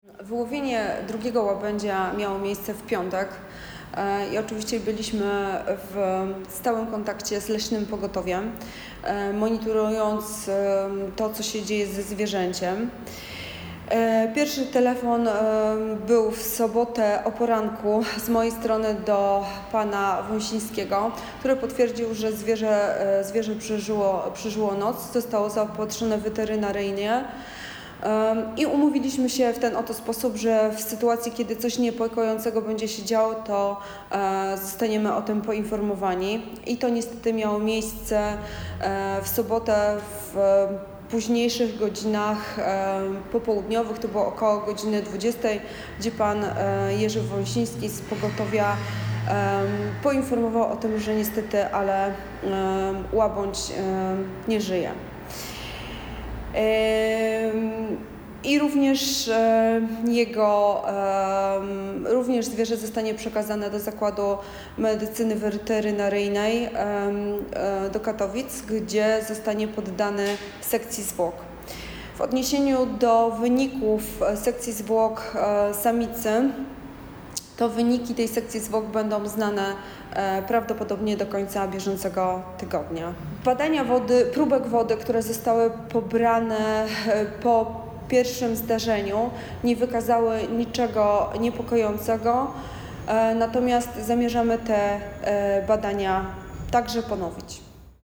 Posłuchaj wypowiedzi władz Chorzowa: